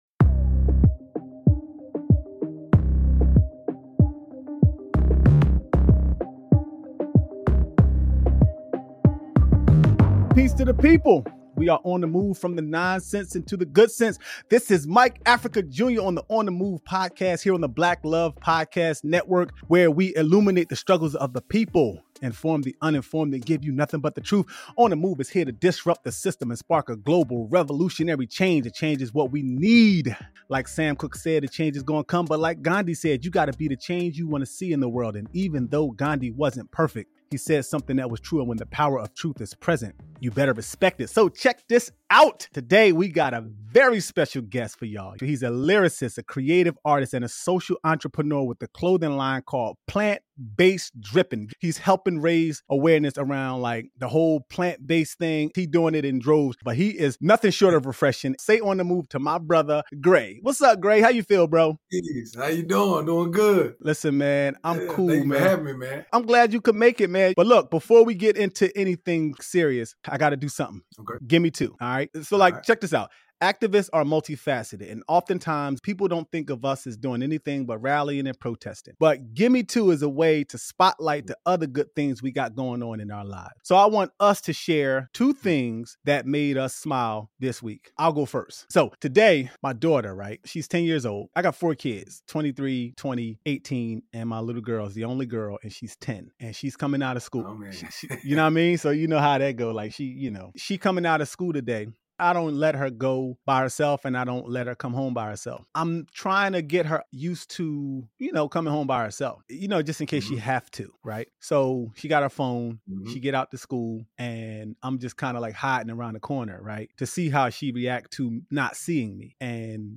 Conversations
Interviews